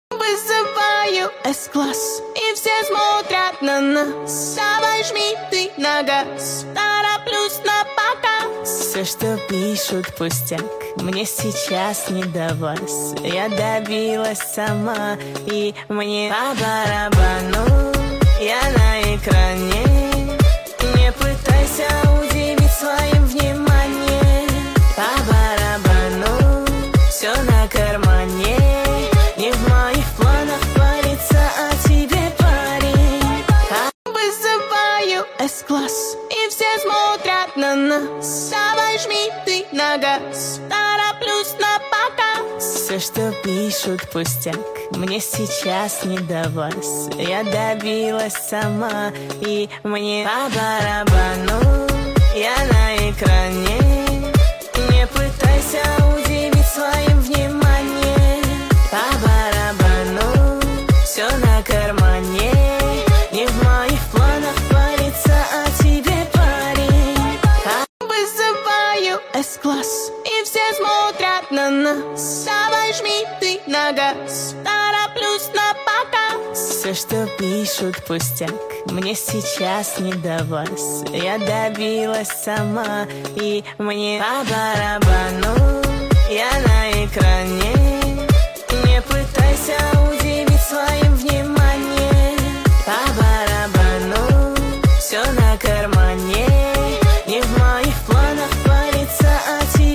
Поп музыка